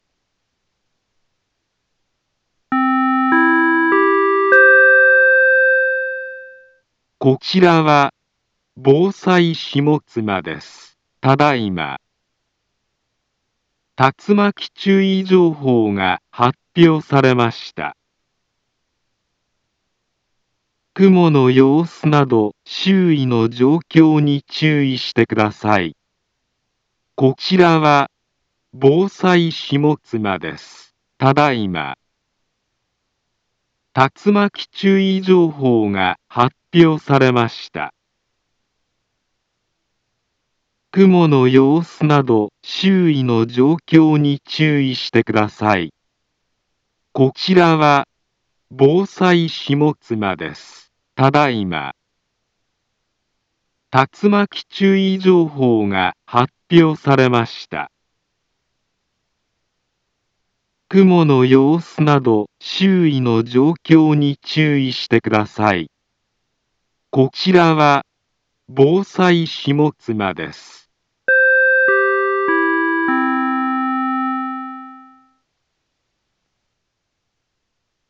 Back Home Ｊアラート情報 音声放送 再生 災害情報 カテゴリ：J-ALERT 登録日時：2024-07-22 20:39:10 インフォメーション：茨城県北部、南部は、竜巻などの激しい突風が発生しやすい気象状況になっています。